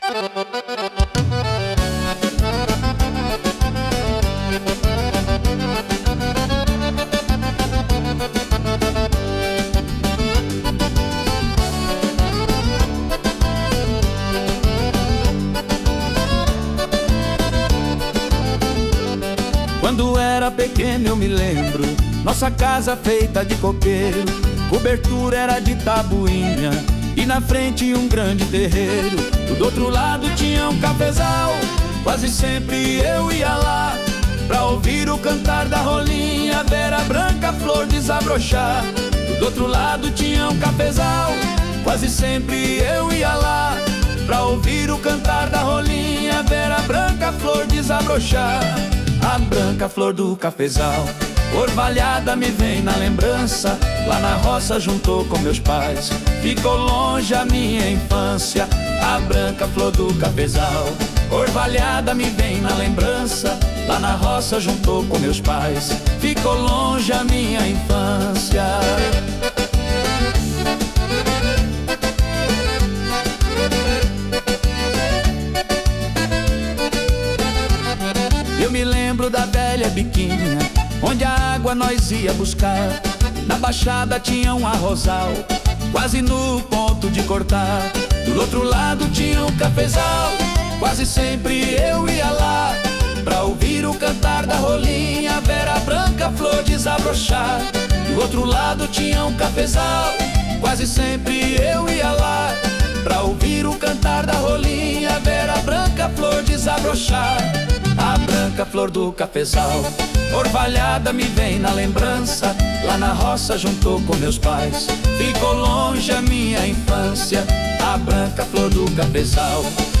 01-CD_A Branca Flor do Cafezal - Bugio + Acordeon.wav